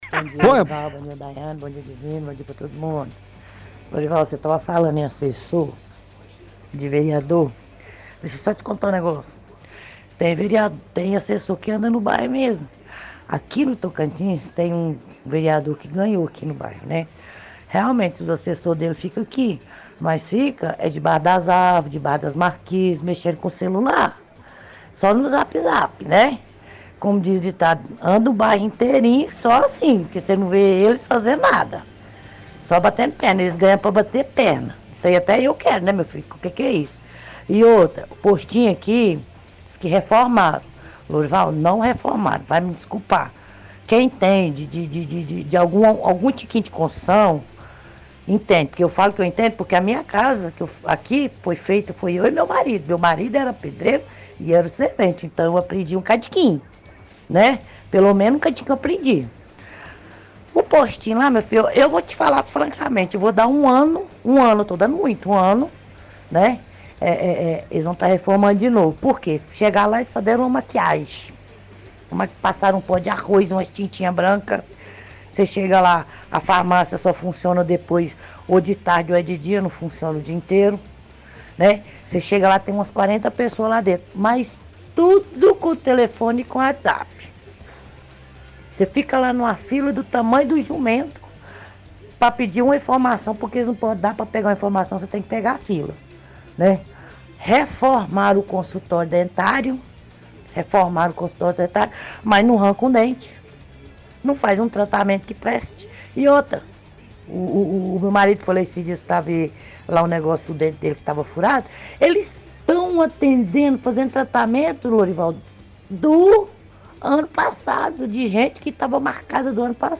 – Ouvinte fala que assessores de vereadores ficam no bairro Tocantins só mexendo no celular porque não fazem nada, ela comenta também sobre a reforma do postinho de saúde do bairro, diz que só “deram uma maquiagem”, farmácia não funciona 24h, ninguém trabalha direito, ela reclama também da quantidade de filas. Reformaram o consultório dentário, mas ninguém é atendido, estão fazendo tratamento de pessoas que estavam na fila desde o ano passado.